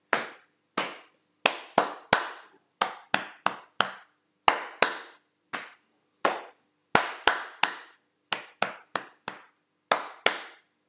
La phrase en percussions corporelles :
Percussions corporelles
percussion_corporelle.mp3